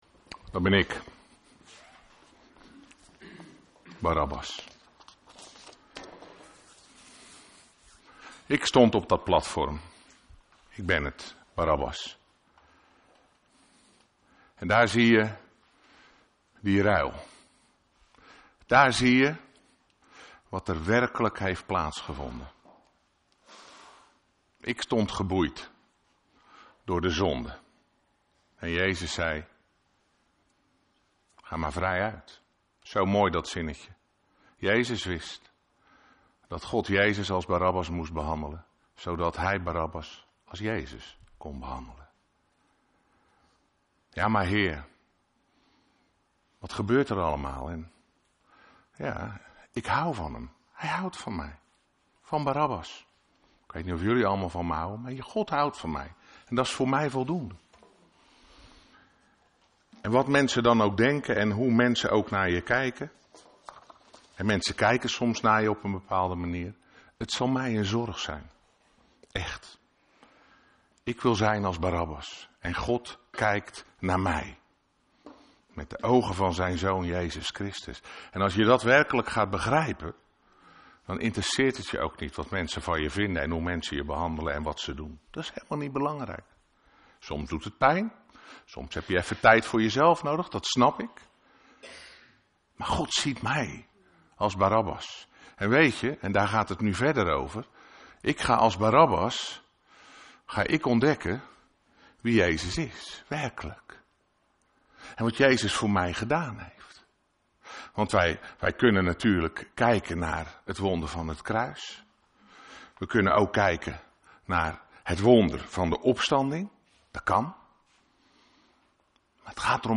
Een preek over Psalm 23